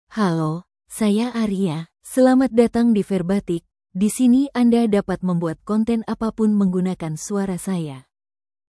AriaFemale Indonesian AI voice
Aria is a female AI voice for Indonesian (Indonesia).
Voice sample
Listen to Aria's female Indonesian voice.
Female